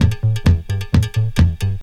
DISCO LOO03L.wav